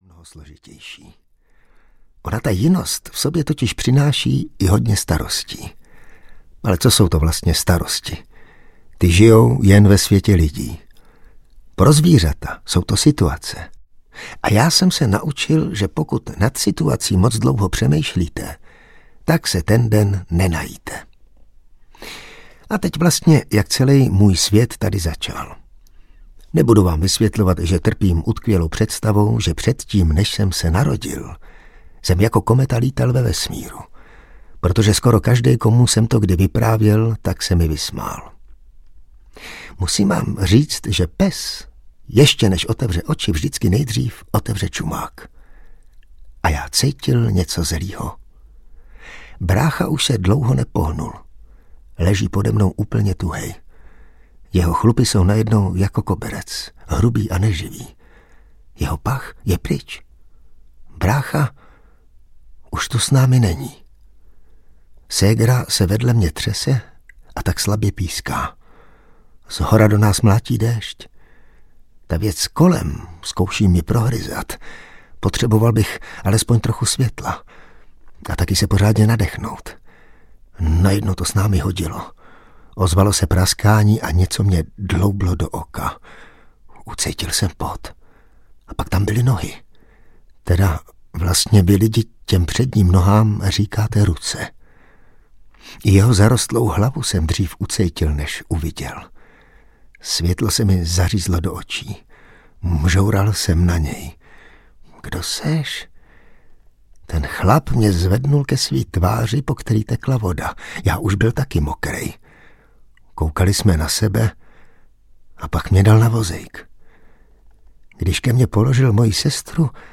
GUMP - pes, který naučil lidi žít audiokniha
Ukázka z knihy
• InterpretIvan Trojan